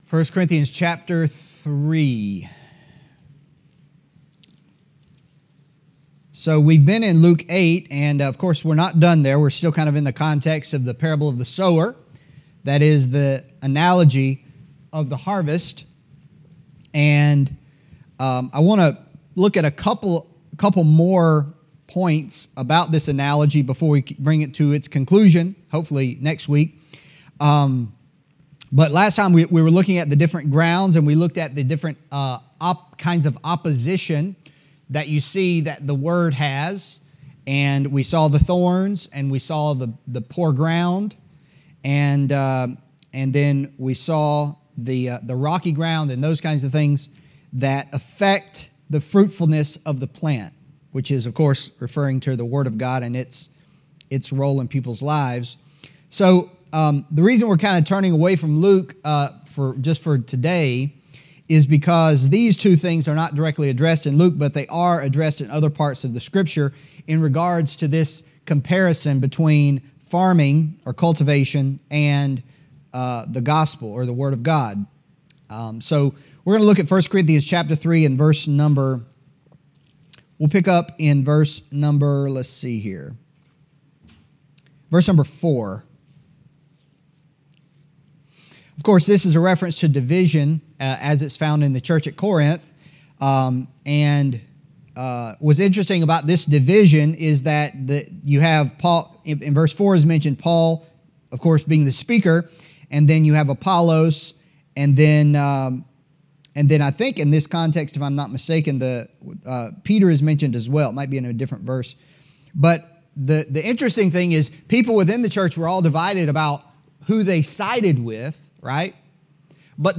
Adult Sunday School